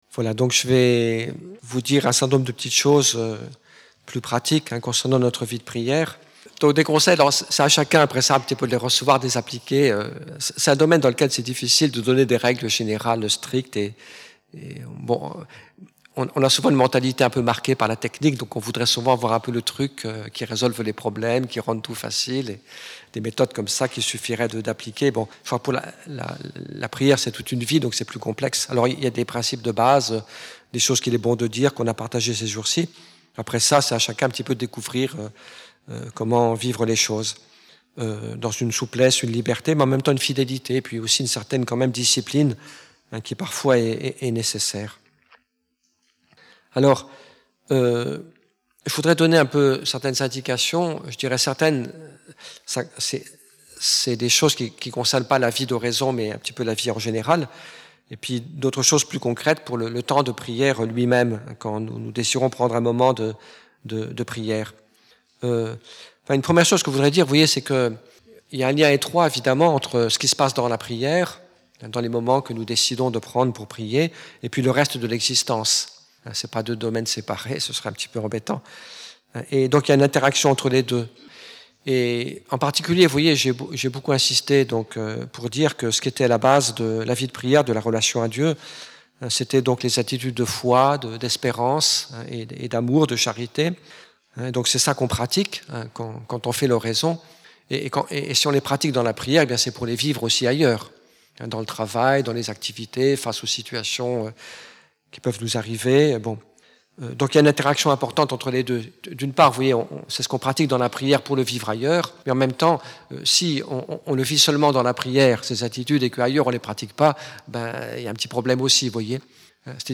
Un CD MP3* regroupant 6 enseignements: